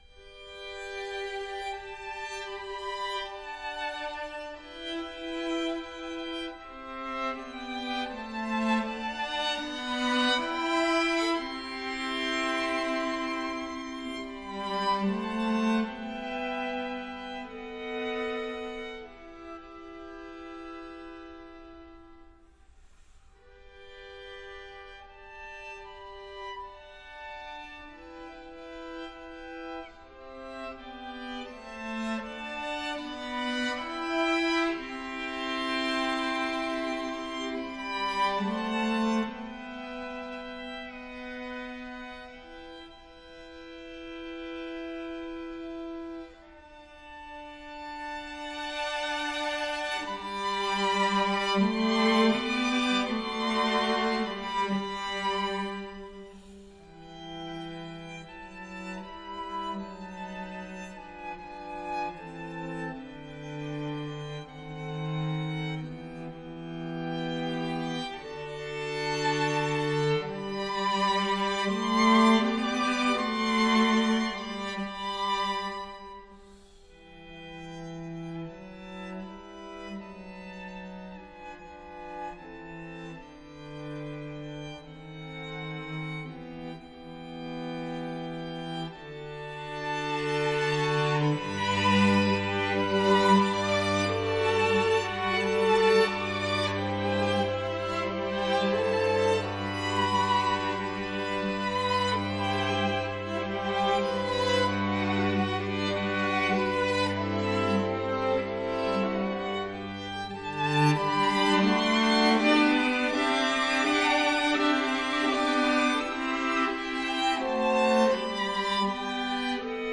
a single-movement work for string quartet